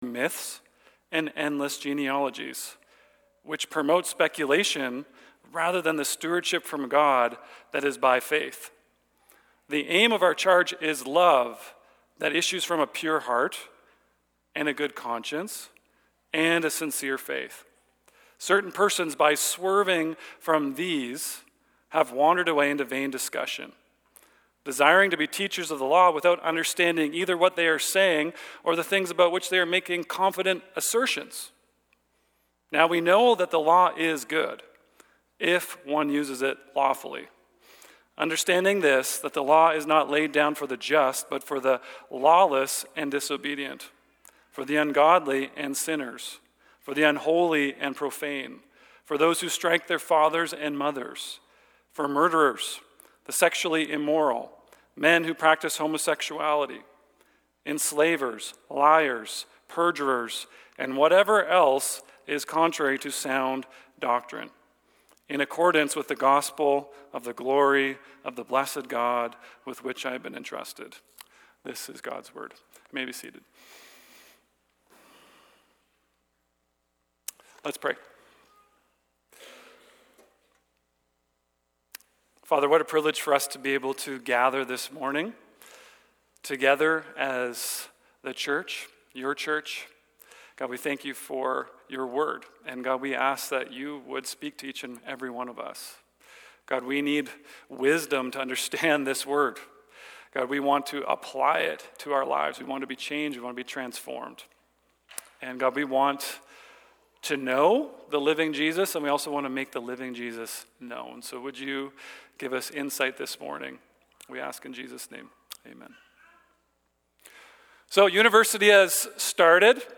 Sermons | Brooks Evangelical Free Church